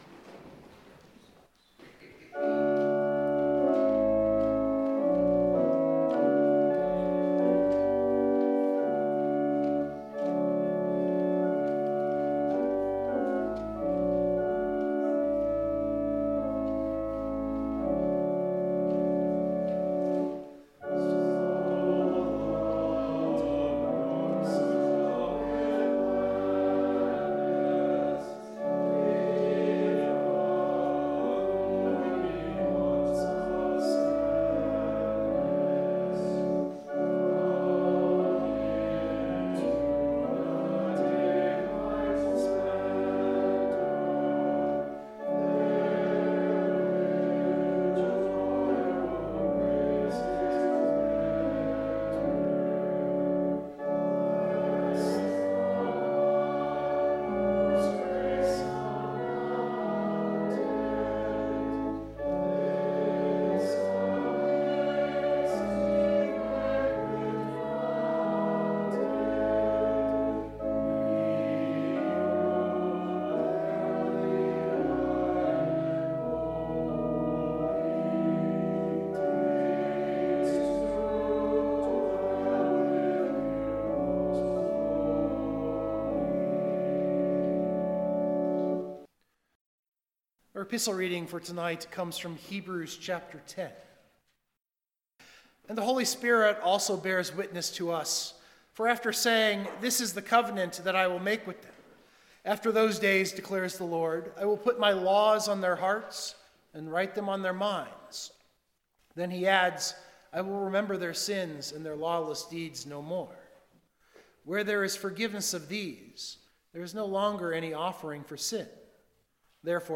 And he does this by giving us his body and blood with the bread and wine. This sermon is a reflection on that great miracle.
MT19MBsermon.mp3